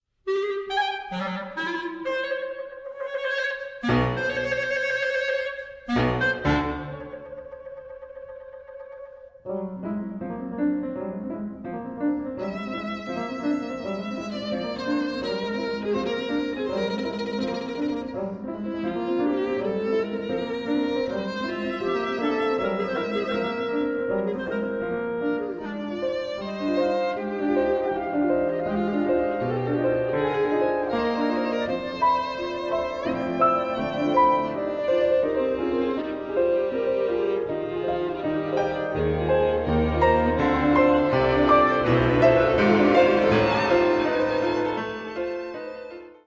Stereo
for B flat Clarinet, Viola and Piano (1996)